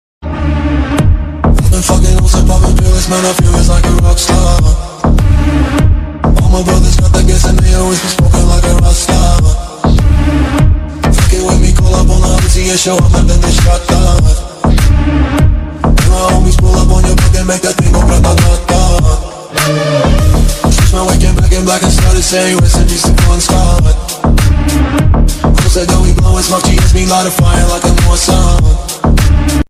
Category: BGM